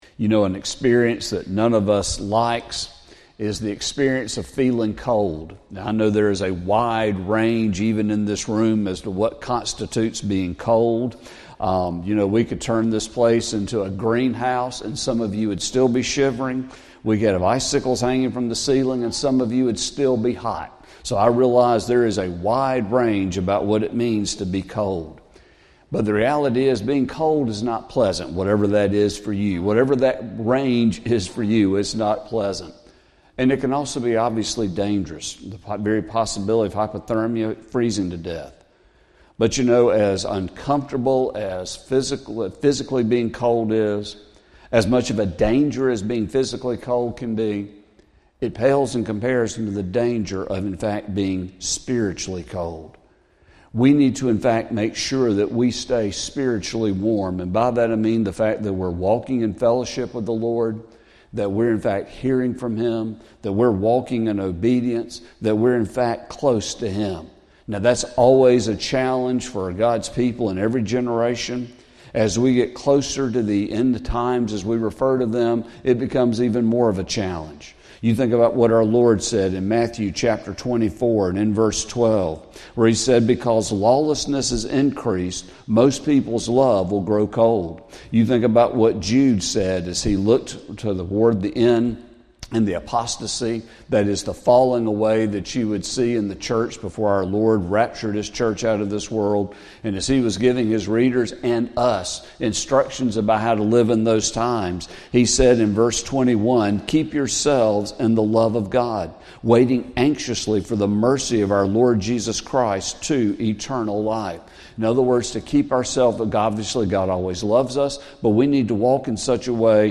Sermon | March 16, 2025